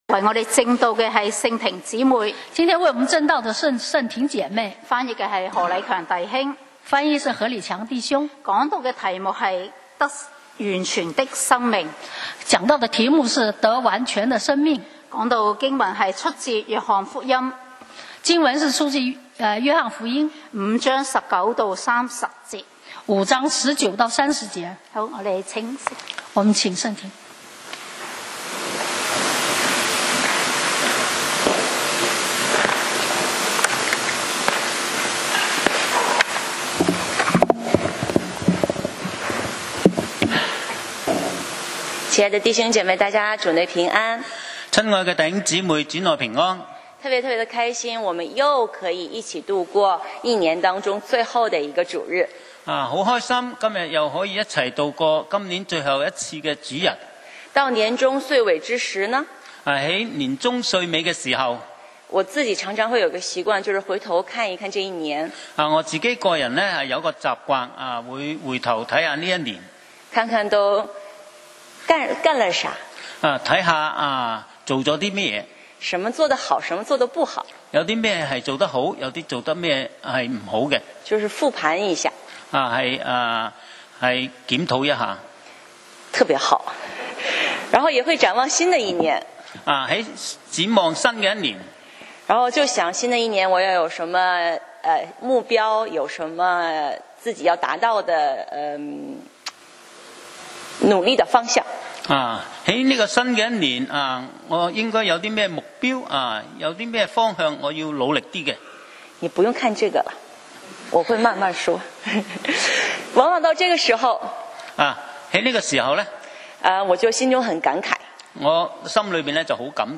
講道 Sermon 題目 Topic： 得完全的生命！